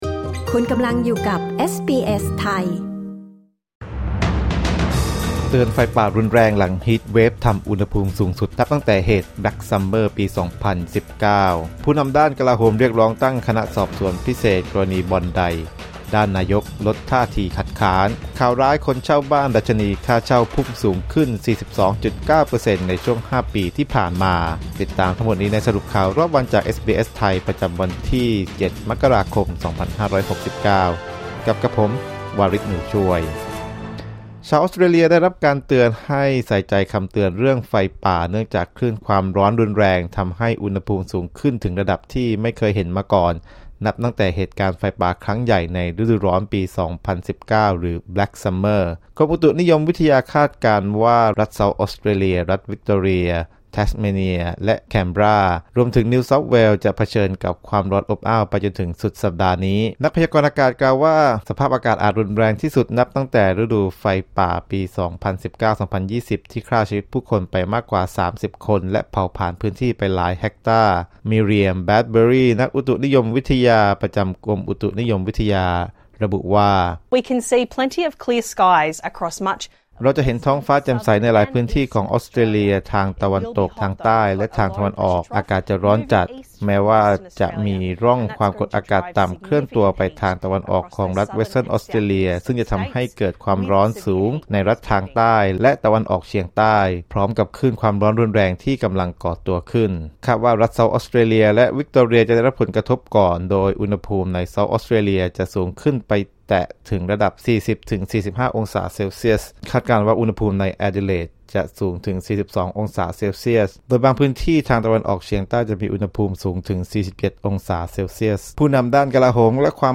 สรุปข่าวรอบวัน 7 มกราคม 2569